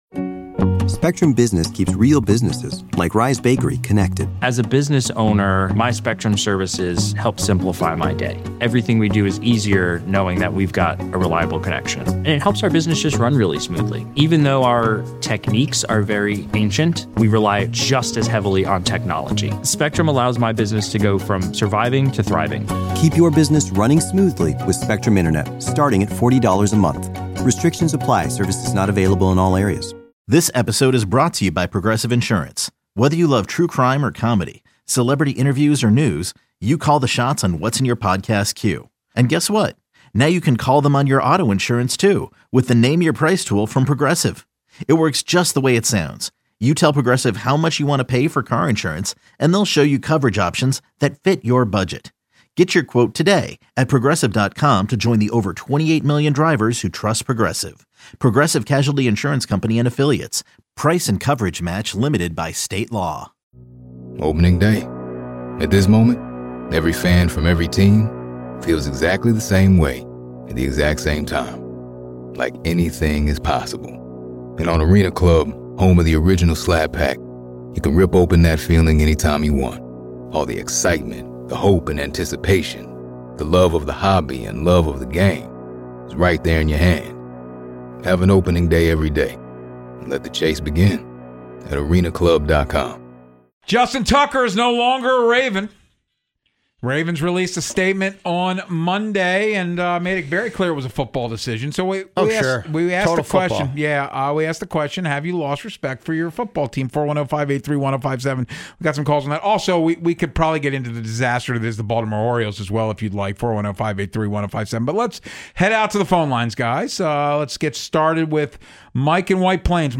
The guys take calls to discuss if they have lost respect for the Ravens handling of the Justin Tucker situation.